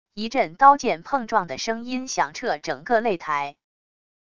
一阵刀剑碰撞的声音响彻整个擂台wav音频